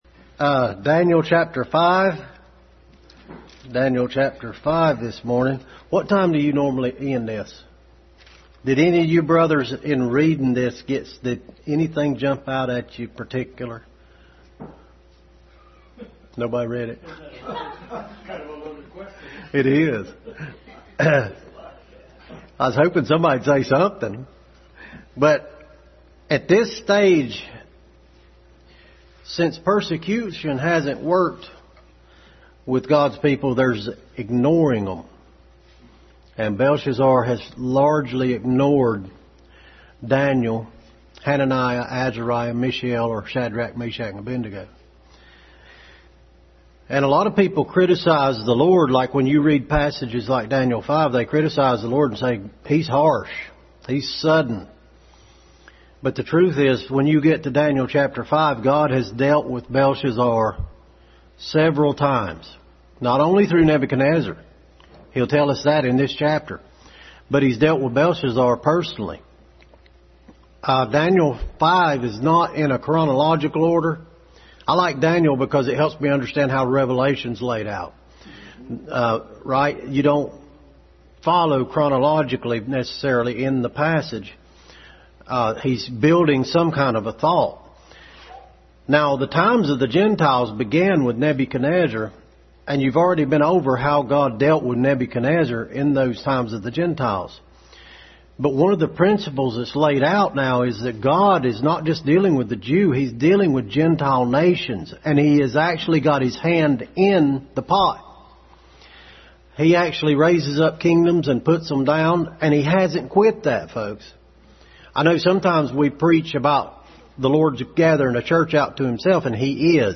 Daniel 5:1-30 Passage: Daniel 5:1-30 Service Type: Sunday School